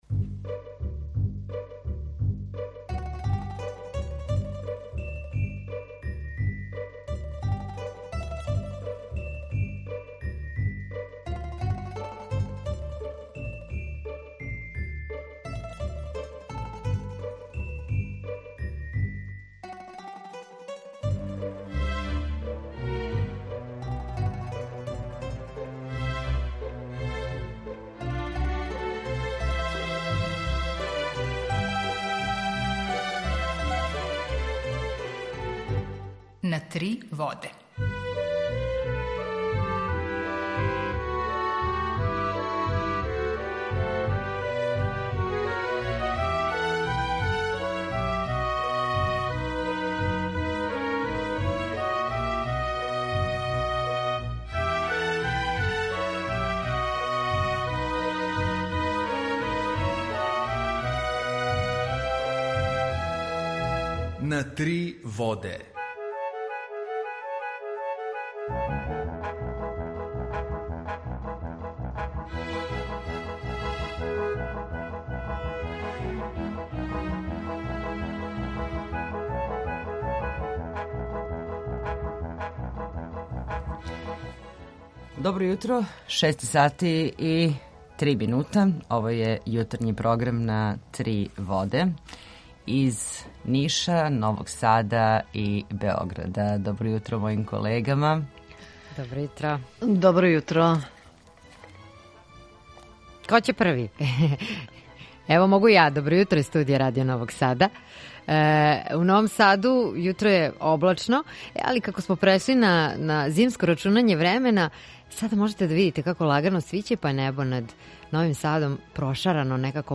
Укључење из Грачанице
Јутарњи програм из три студија
У два сата, ту је и добра музика, другачија у односу на остале радио-станице.